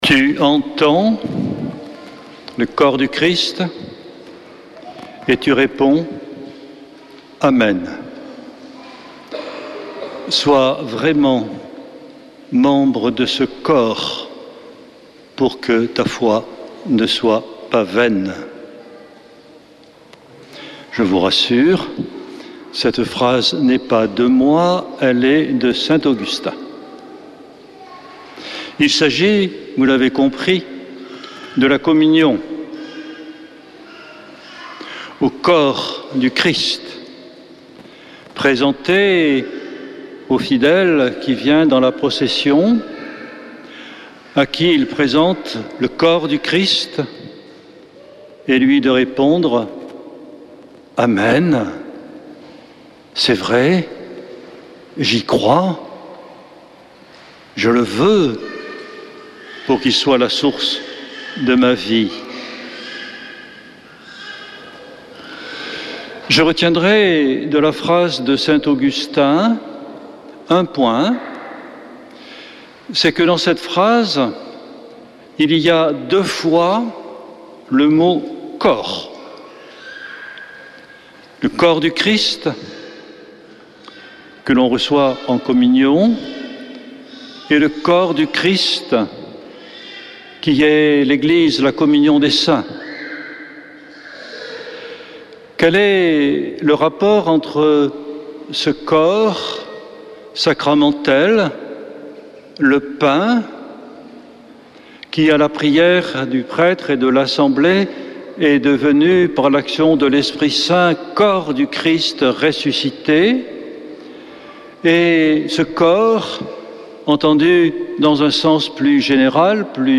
dimanche 25 août 2024 Messe depuis le couvent des Dominicains de Toulouse Durée 01 h 30 min
Homélie du 25 août